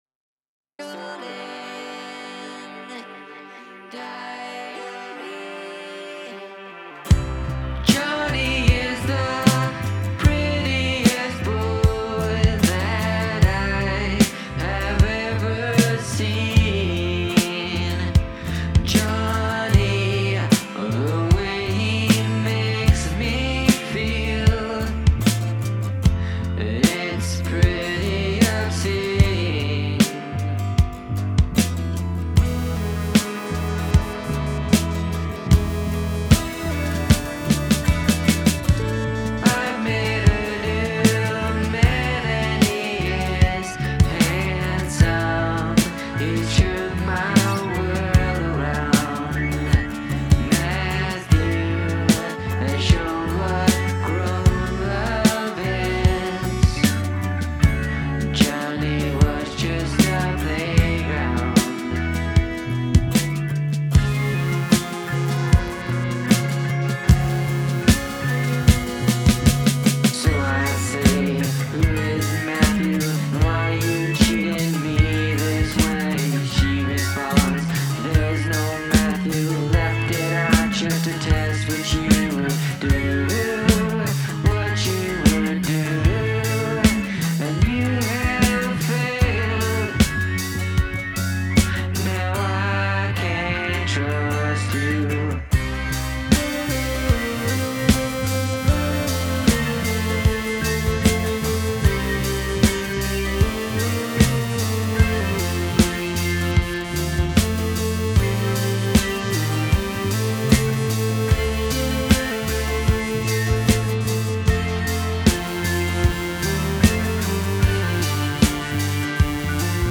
Vocal Harmony